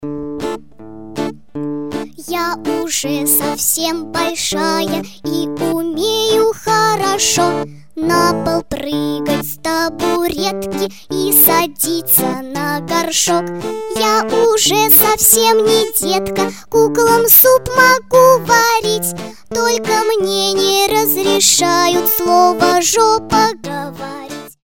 гитара
веселые
детский голос
смешные
детские